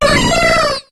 Cri de Cotovol dans Pokémon HOME.